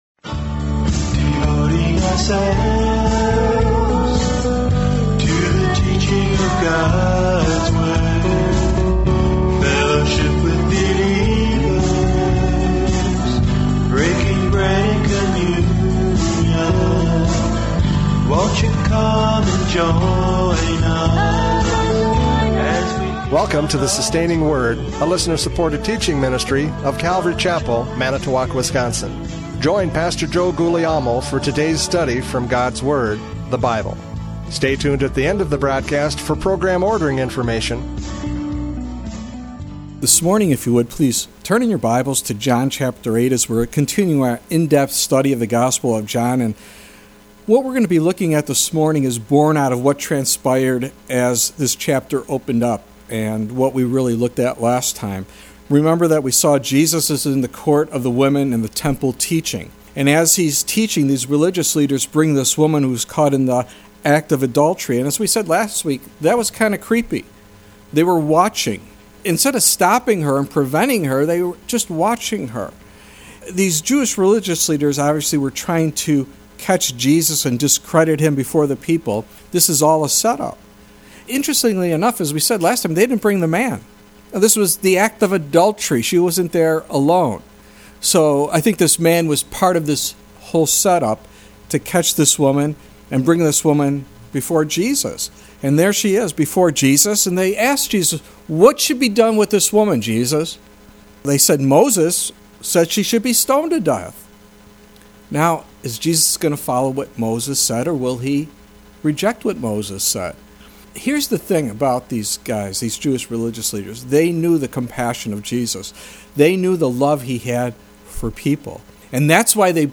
John 8:12-20 Service Type: Radio Programs « John 8:1-11 Forgiven!